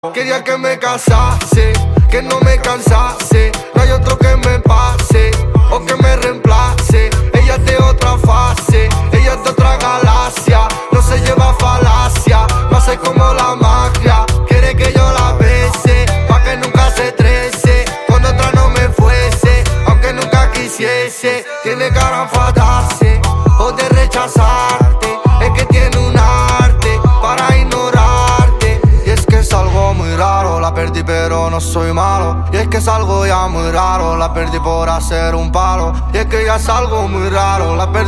Categoría Rap